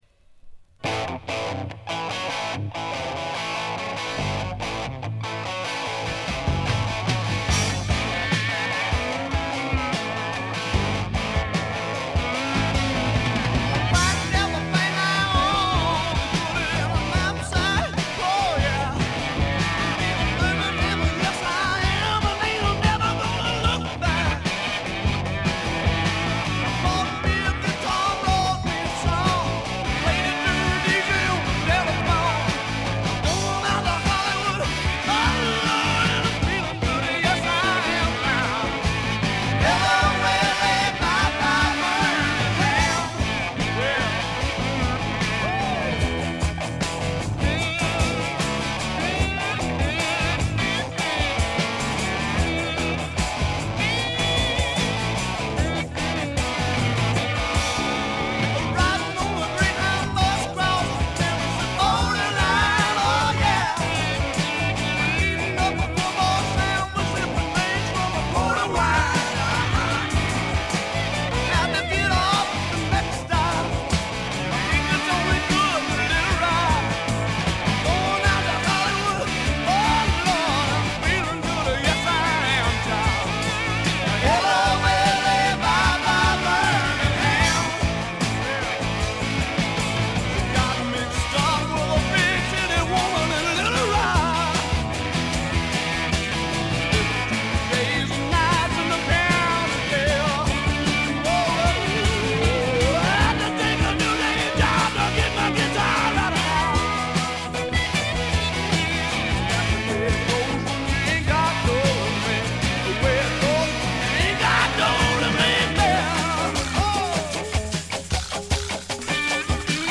濃厚なスワンプ味に脳天直撃される傑作です。
試聴曲は現品からの取り込み音源です。